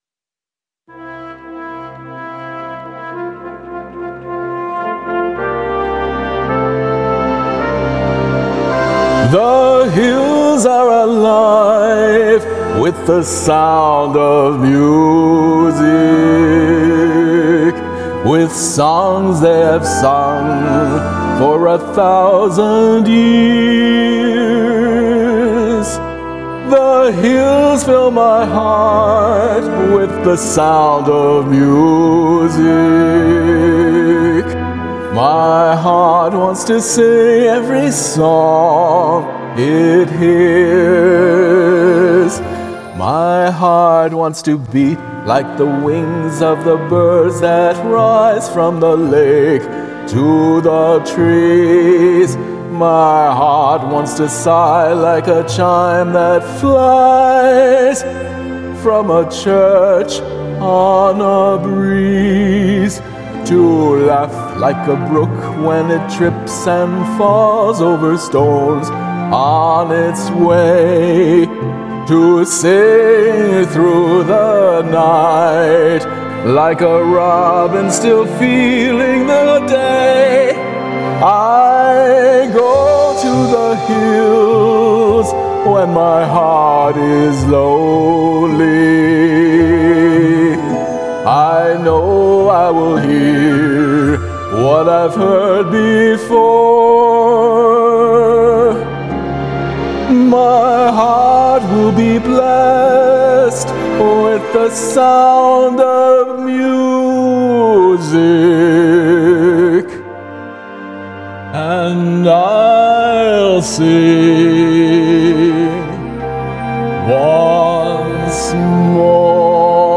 I'm singing to a karaoke orchestral version of the song